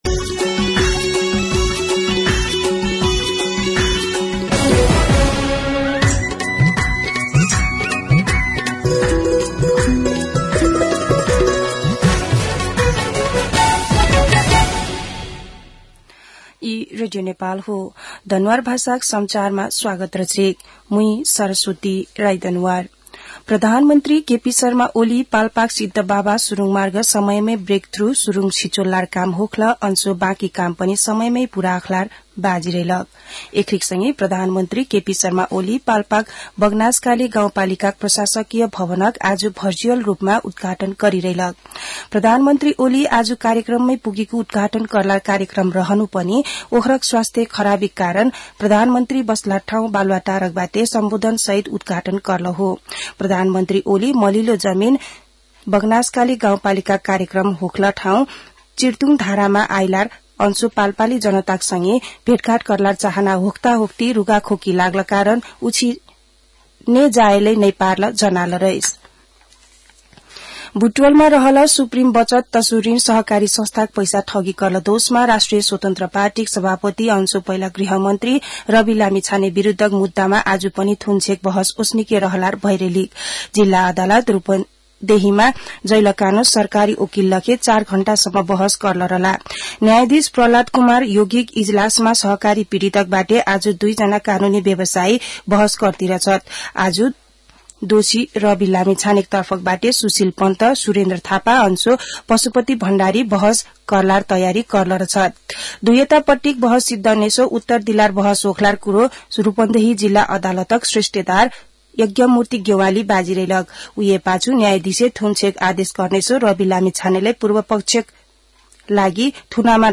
दनुवार भाषामा समाचार : १२ माघ , २०८१
Danuwar-news-8.mp3